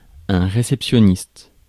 Ääntäminen
Ääntäminen France: IPA: [ɛ̃ ʁe.sɛp.sjɔ.nist] Tuntematon aksentti: IPA: /ʁe.sɛp.sjɔ.nist/ Haettu sana löytyi näillä lähdekielillä: ranska Käännös Substantiivit 1. akceptistino Suku: m .